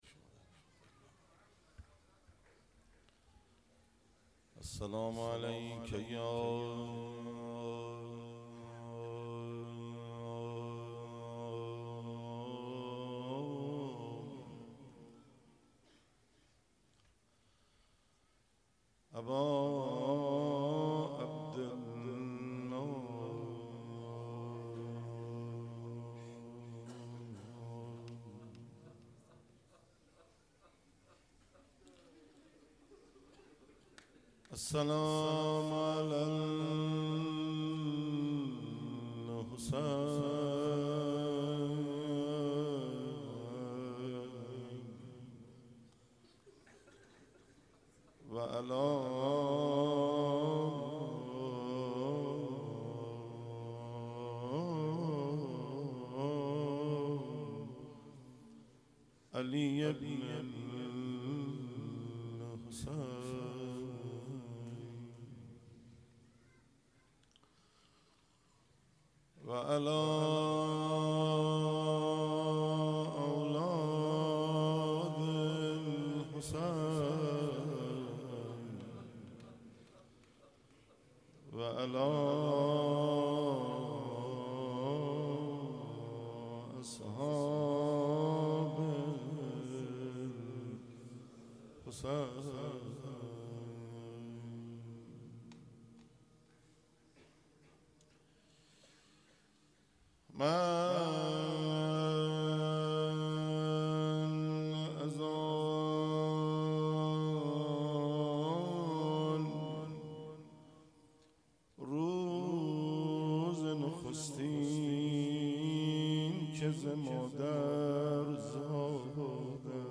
روضه
02 heiate eradat mandan be hazrat zahra .mp3